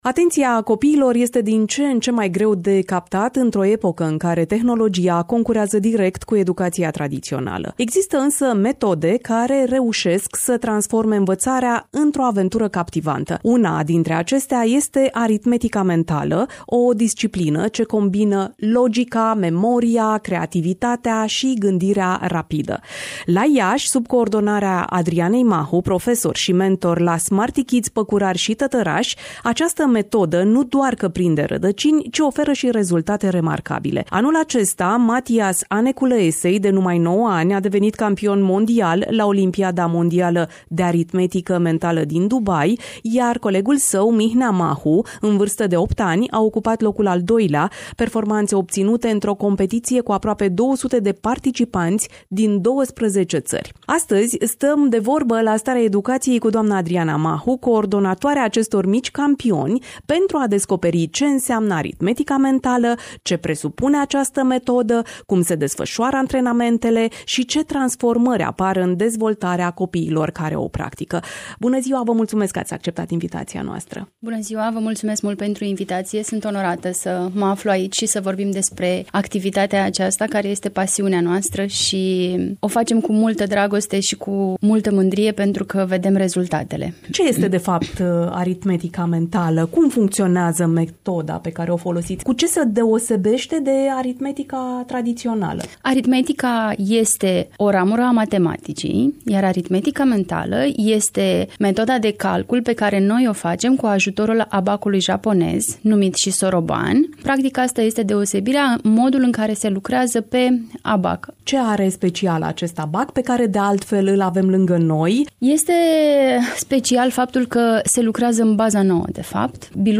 Varianta audio a interviului